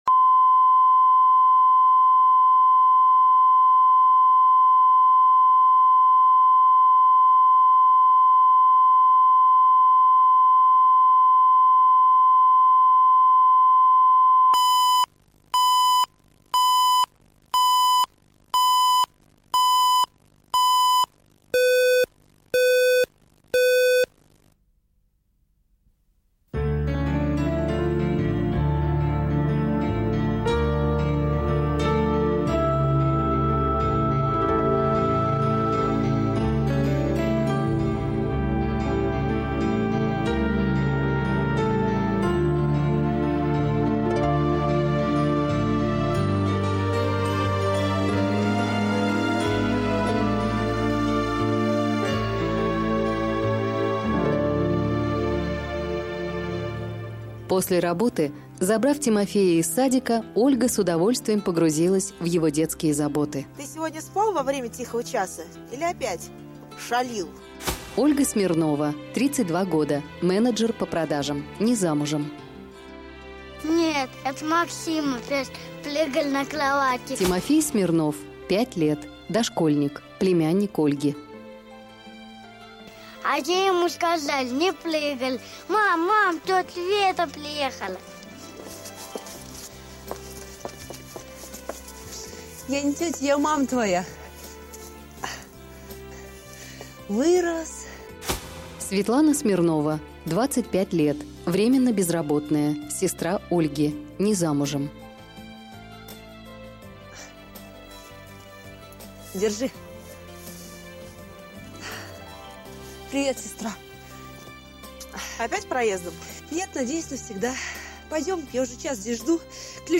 Аудиокнига Мама Оля | Библиотека аудиокниг